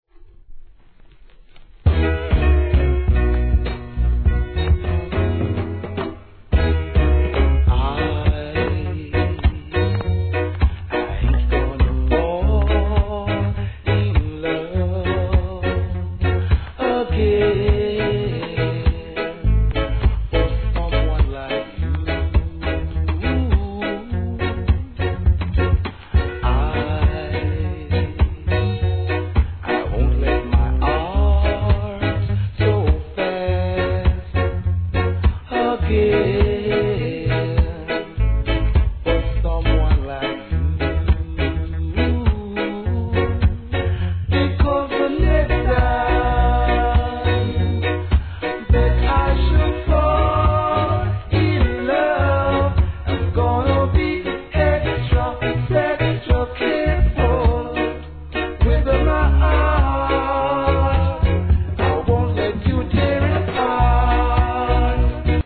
REGGAE
甘茶SOULカヴァー♪